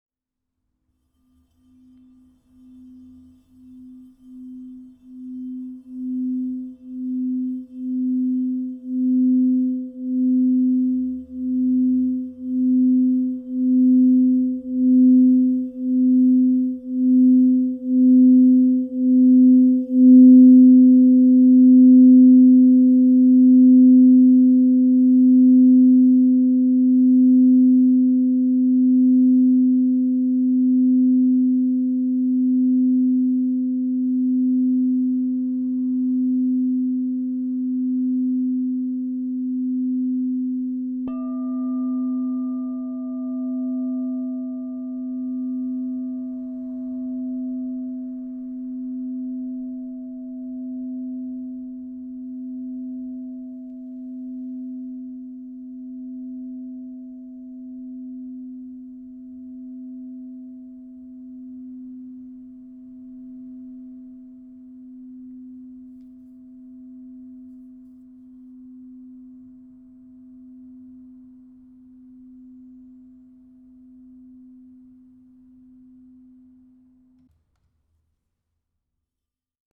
Crystal Tones® Azeztulite 9 Inch C Alchemy Singing Bowl
Elevate your spiritual practice with the Crystal Tones® Azeztulite Triple Alchemy 9 inch C Negative 45 Singing Bowl, resonating at C -45 to inspire celestial connection, emotional clarity, and energetic renewal.
At 9 inches, this bowl produces deep, resonant tones that fill any space with harmonious frequencies, making it perfect for personal meditation, group sessions, or creating a sacred sanctuary.
432Hz (-)